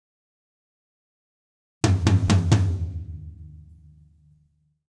Toms 01.wav